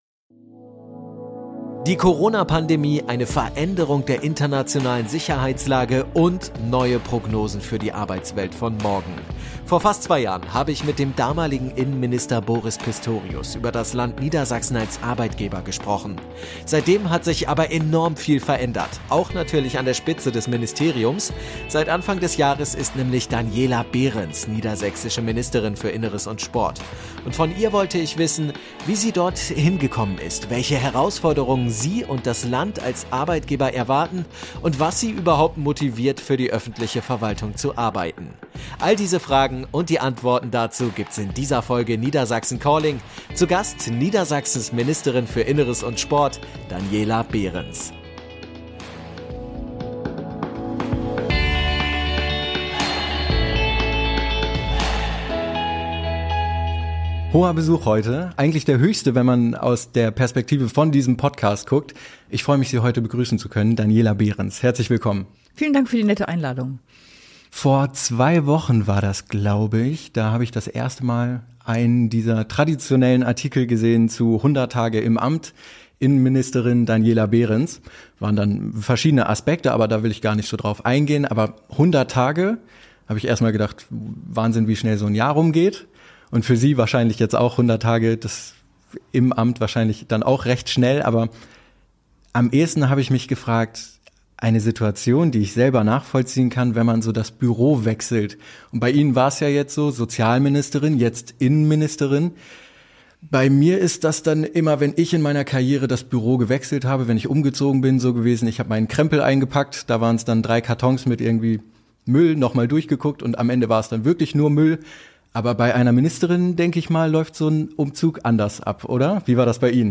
Er ist mit der Ministerin für Inneres und Sport, Daniela Behrens, höchstpersönlich im Gespräch. Sie berichtet unter anderem von ihrem Werdegang – wie sie vom Journalismus zur Politik gekommen ist. Aber nicht nur das: Es geht auch um die Herausforderungen und Aufgaben, die in den nächsten Jahren auf das Land Niedersachsen als Arbeitgeber zukommen, und um die vielen Möglichkeiten, die das Land als Arbeitgeber bietet.